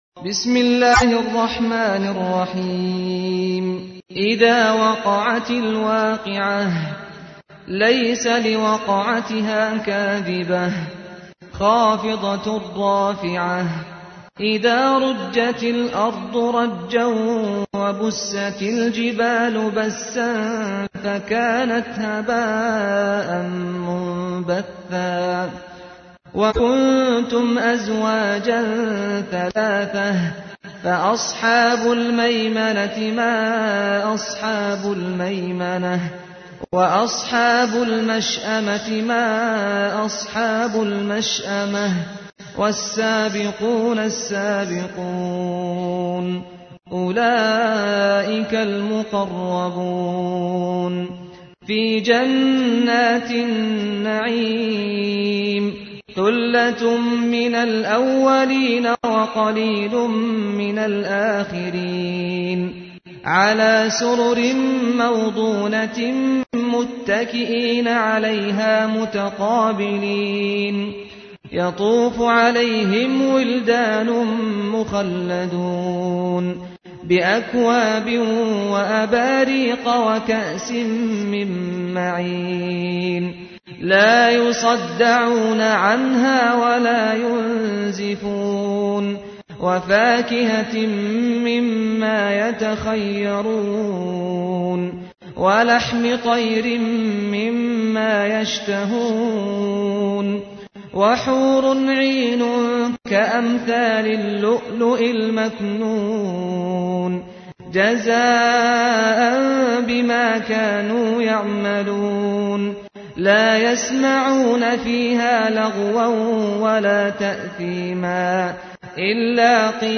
تحميل : 56. سورة الواقعة / القارئ سعد الغامدي / القرآن الكريم / موقع يا حسين